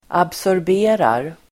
Uttal: [absårb'e:rar]